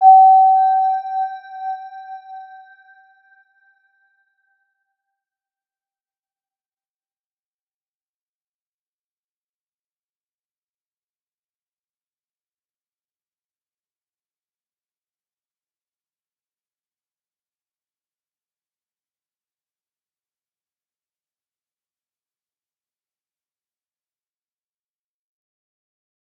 Round-Bell-G5-mf.wav